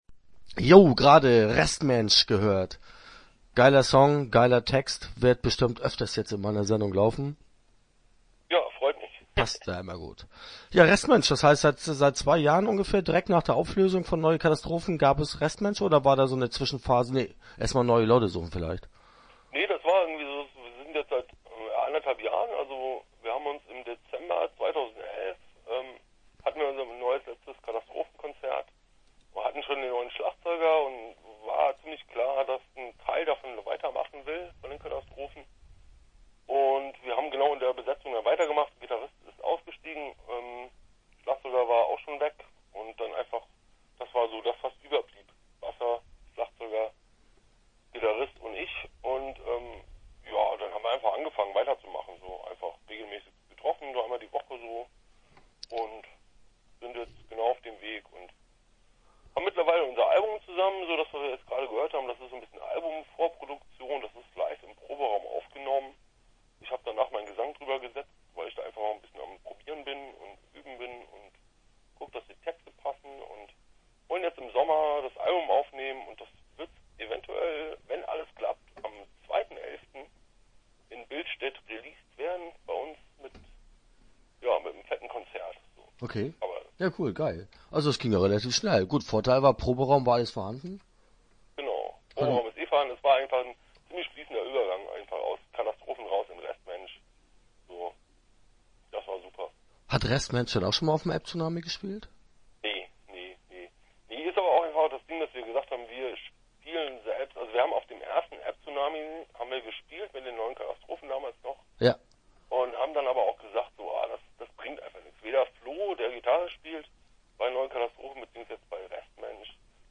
Start » Interviews » Restmensch